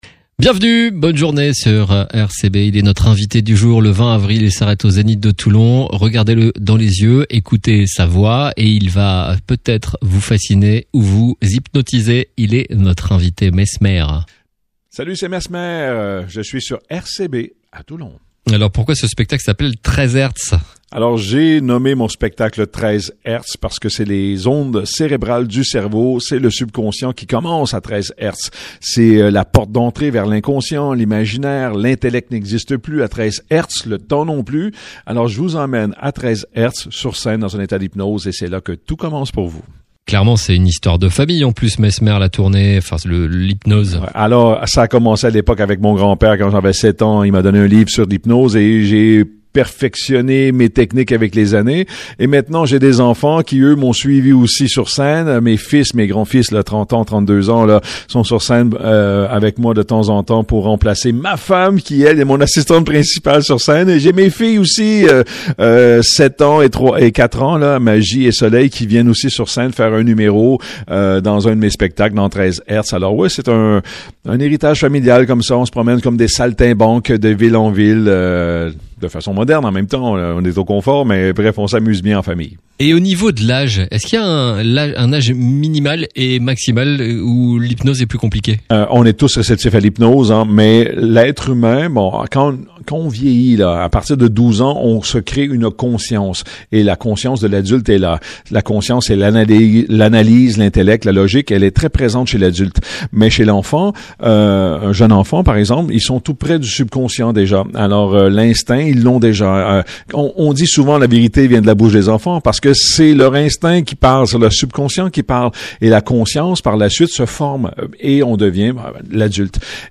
3. Les interviews exclusifs de RCB Radio